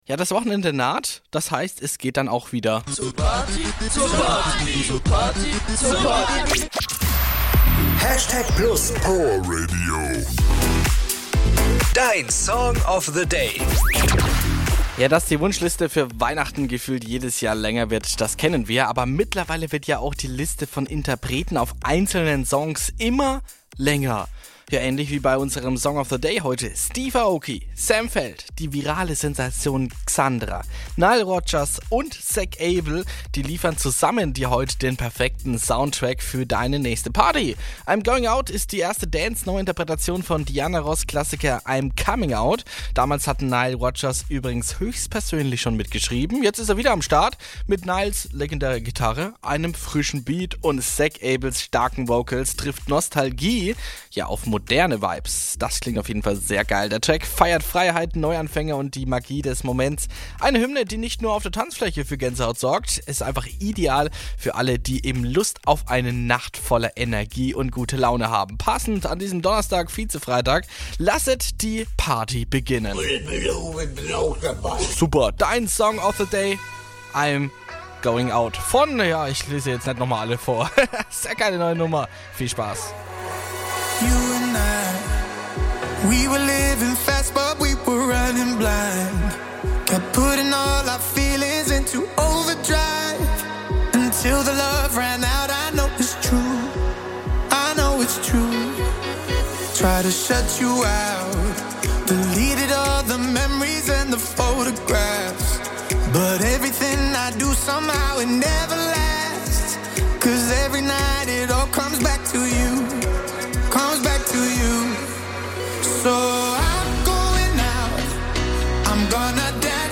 Dance-Neuinterpretation